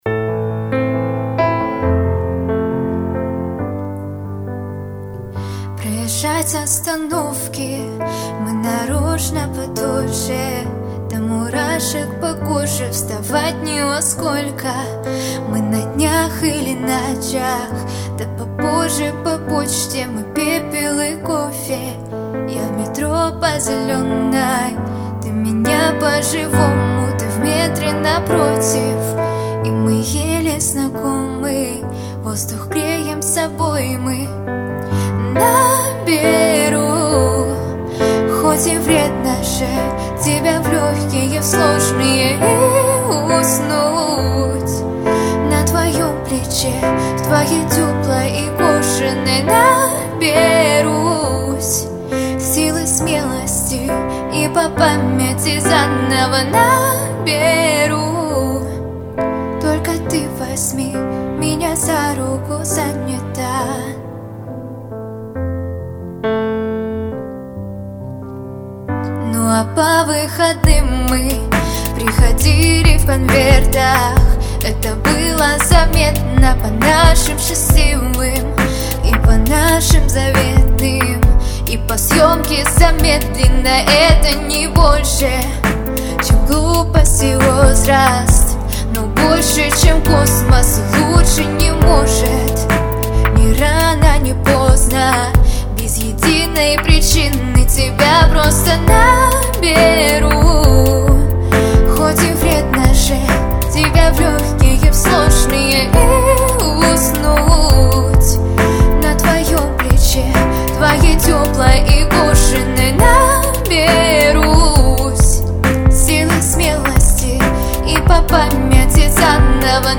Записывали дома. Комната без обработки.